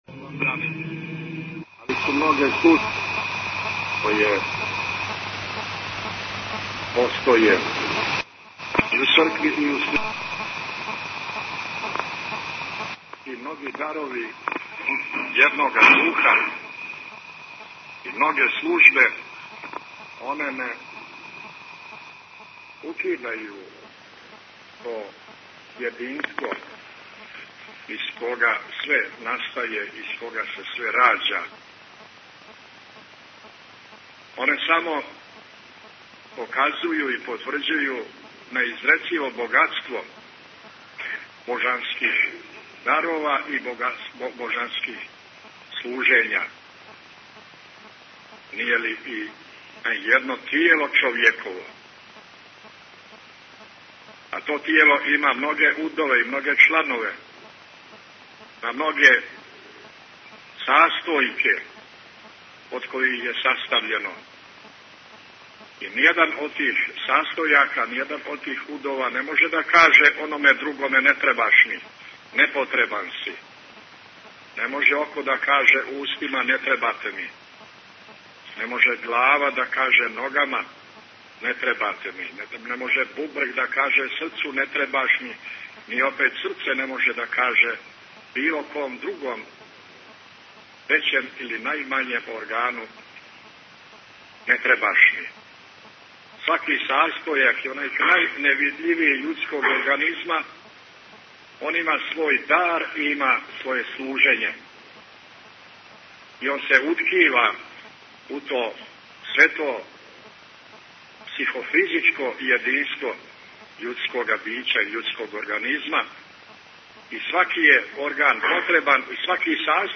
Бесједа Митрополита Амфилохија у цркви Светог великомученика Георгија у Доњој горици у Подгорици, 16. септембар 2007 | Радио Светигора
Tagged: Бесједе Наслов: besjeda Година: 2007 Величина: 21:00 минута (3.61 МБ) Формат: MP3 Mono 22kHz 24Kbps (CBR) Бесједа Његовог Високопреосвештенства Архиепископа Цетињског Митроплита Црногорско-приморског Г. Амфилохија са Свете Архијерејске Литургије коју је у недјељу 16. септембра служио у цркви Светог великомученика Георгија у Доњој горици у Подгорици.